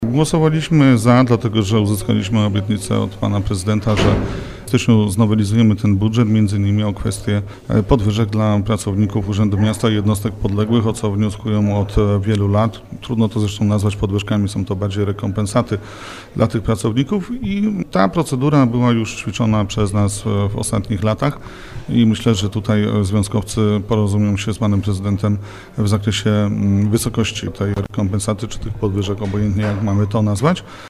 Przewodniczący klubu PiS Jacek Budziński przypomina, że już w styczniu dojdzie do jego nowelizacji, aby znalazły się pieniądze na podwyżki dla pracowników jednostek miejskich: